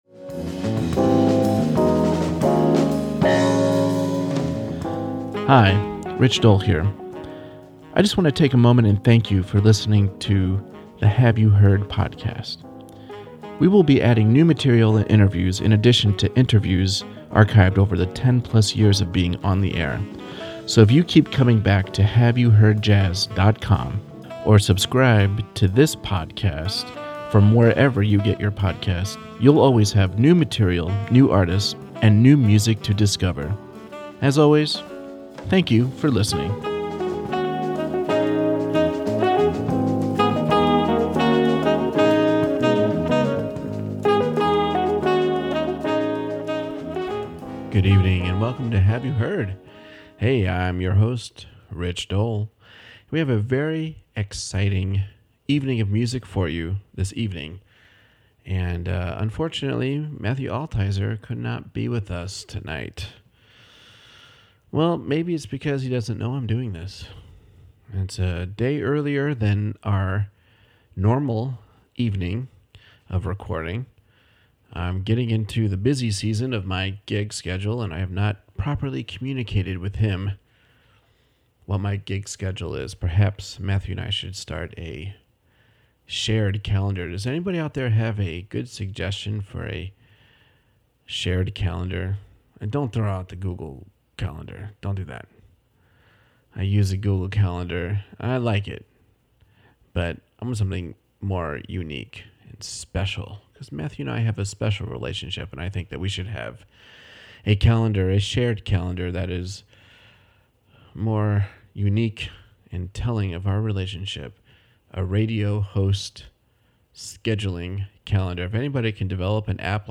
Well, we kept scheduling and re-scheduling interviews and finally she put her foot down and recorded a GREAT album which would force me to book her on the show and play her music!